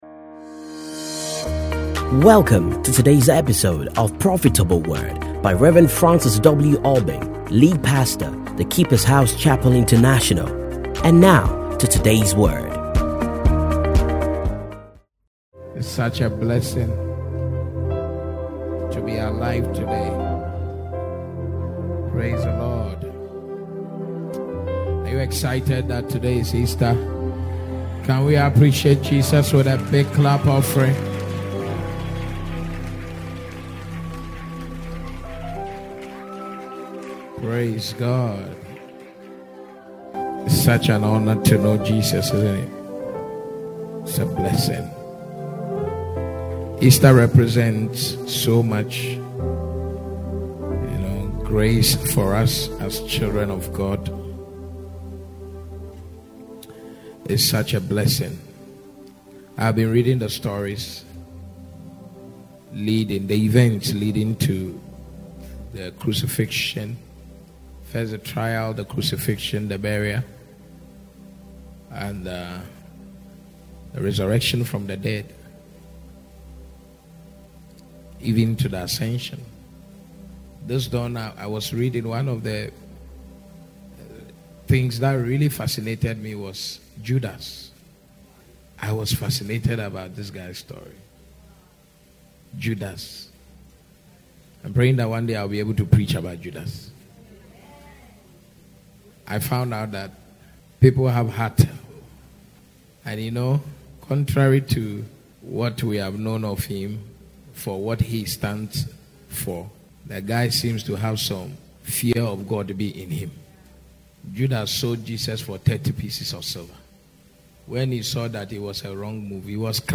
Stay Connected And Enjoy These Classic Sermons